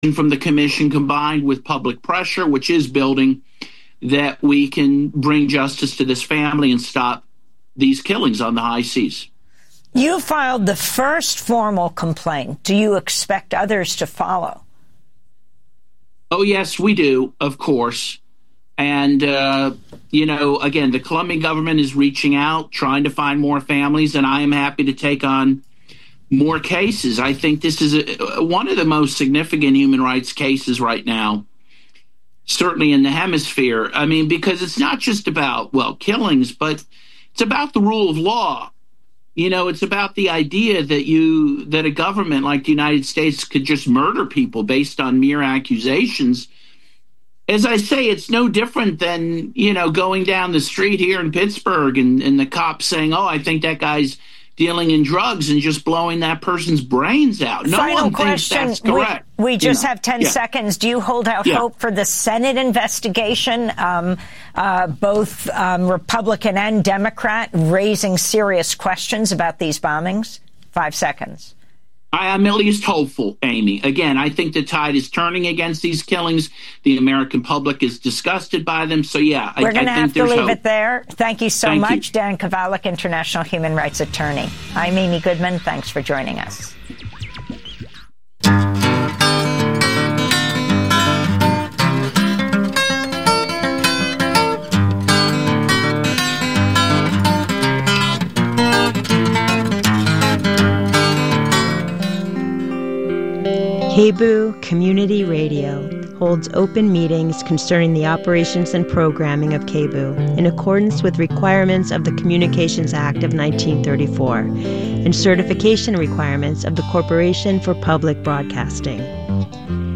Friday Talk Radio